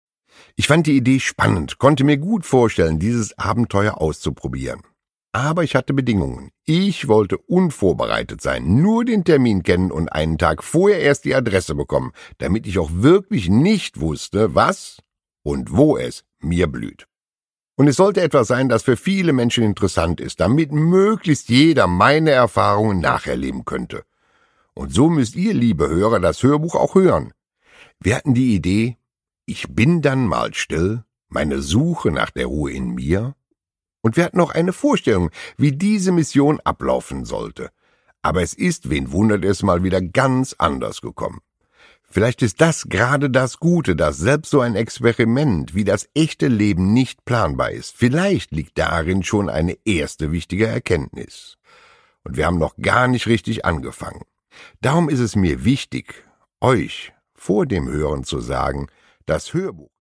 Produkttyp: Hörbuch-Download
Gelesen von: Horst Lichter